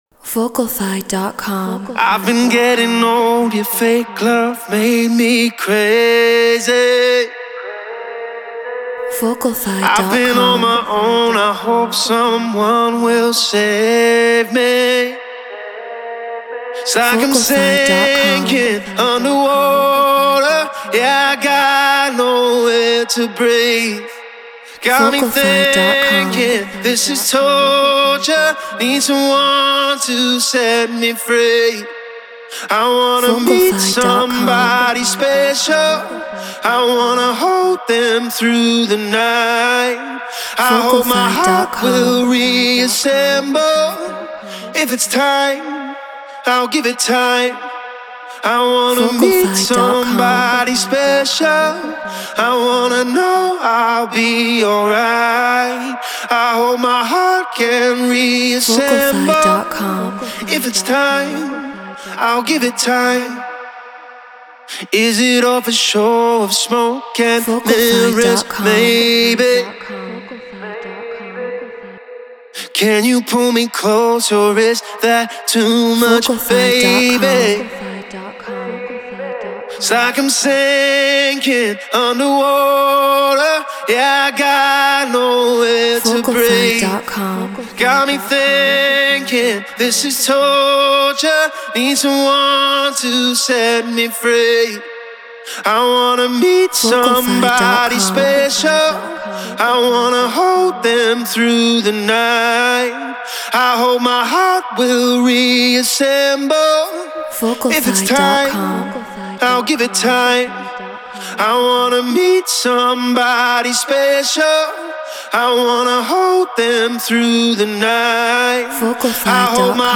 Deep House 122 BPM G#min